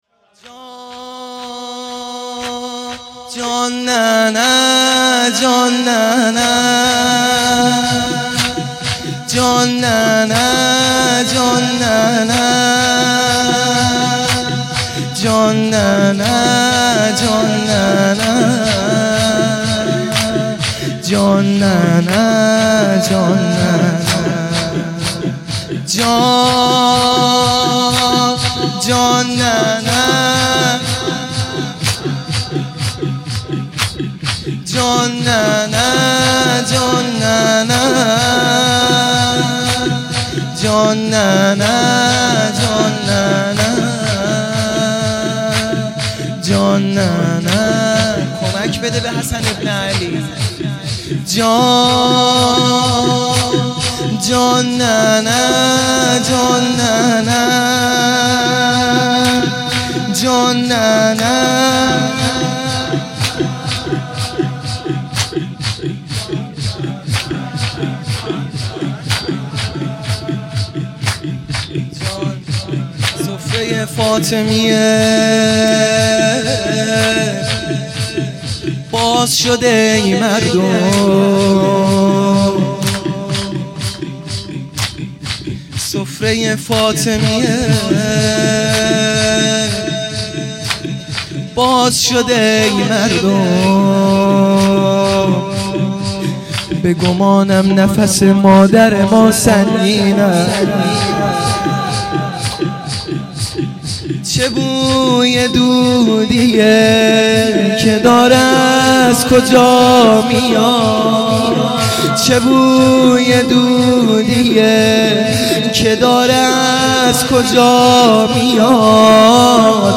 لطمه زنی | چه بوی دودیه
فاطمیه اول 1396